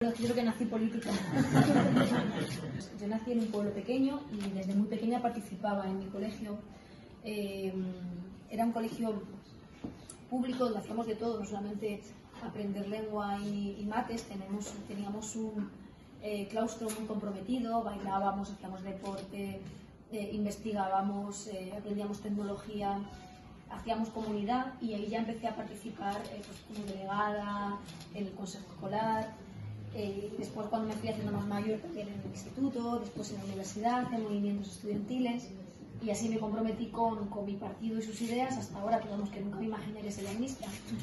La ministra portavoz del Gobierno es entrevistada por alumnos y alumnas de la ONCE en Madrid - PortalONCE